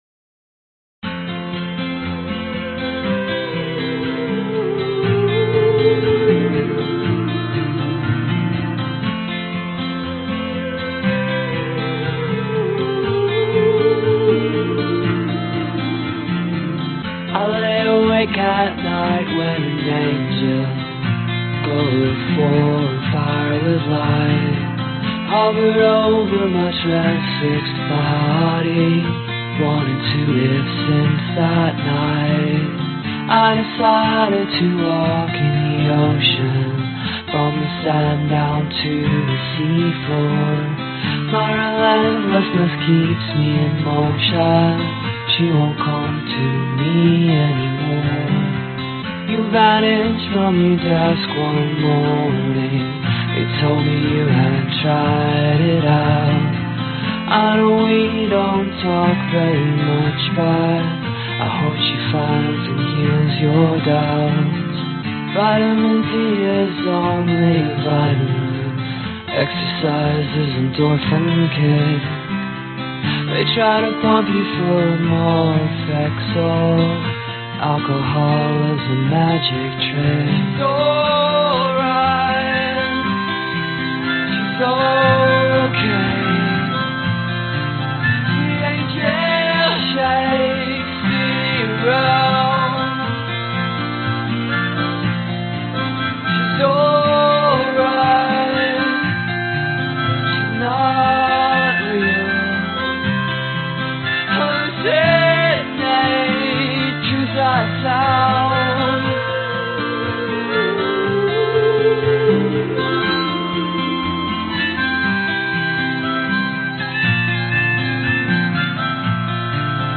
Tag: 男声 女声 贝斯 钢琴 器乐